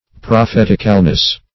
Search Result for " propheticalness" : The Collaborative International Dictionary of English v.0.48: Propheticalness \Pro*phet"ic*al*ness\, n. The quality or state of being prophetical; power or capacity to foretell.
propheticalness.mp3